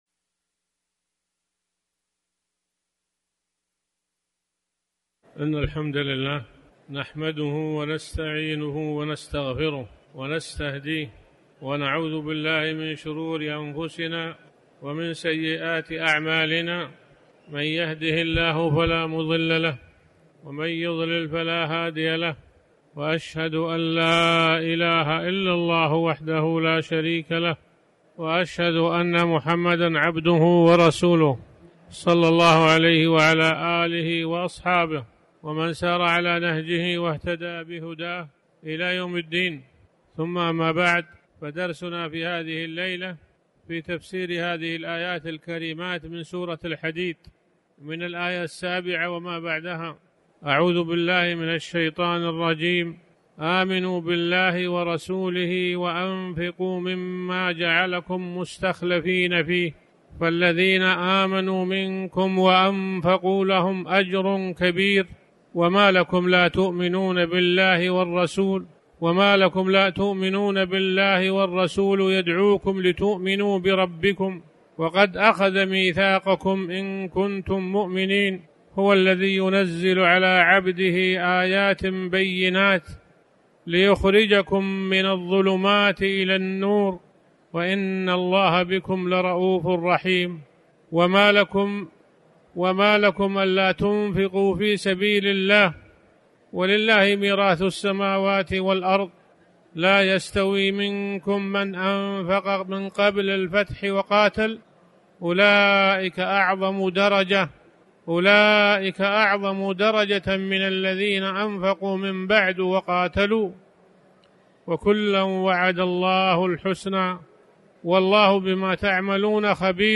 تاريخ النشر ٢٨ شوال ١٤٤٠ هـ المكان: المسجد الحرام الشيخ